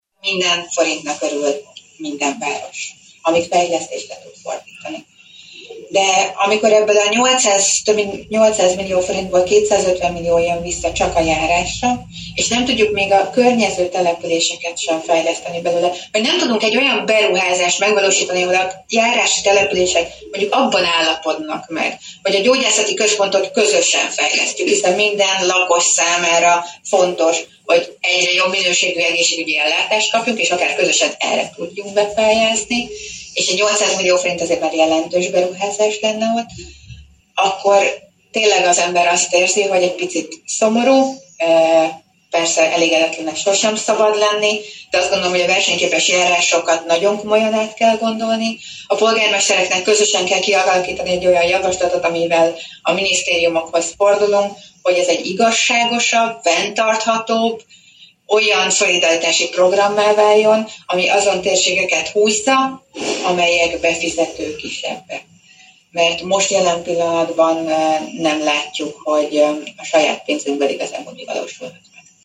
Komolyan át kell gondolni a Versenyképes Járások Program működését Heringes Anita Paks polgármestere szerint ugyanis a Paks által befizetett közel 800 millió forintos összegből mindössze 250 millió jön vissza a térségnek. A paksi városvezető szerint nem szerencsés, hogy a befizetett összeg nagyobb része az állami költségvetésnél marad és nem a térségre kerül elköltésre, melyről a november 12-ei rendkívüli testületi ülést követő sajtótájékoztatón beszélt.
heringes_hirek_int.mp3